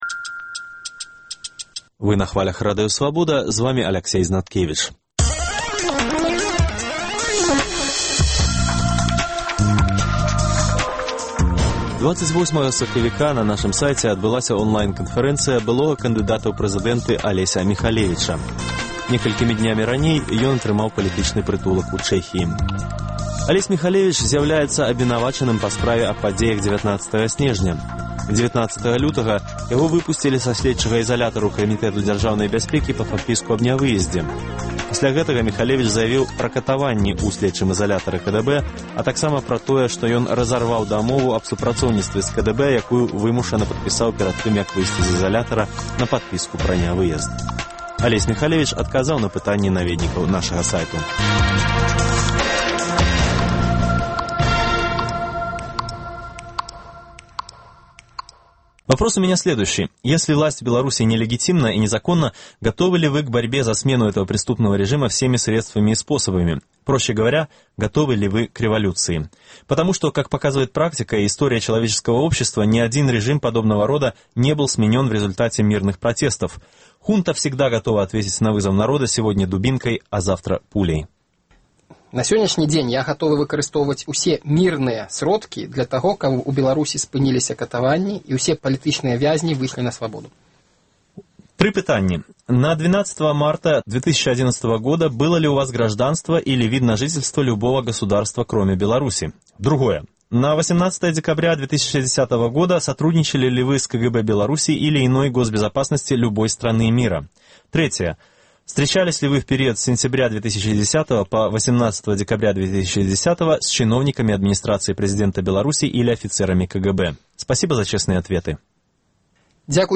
Онлайн-канфэрэнцыя
Онлайн-канфэрэнцыя з былым кандыдатам у прэзыдэнты Алесем Міхалевічам.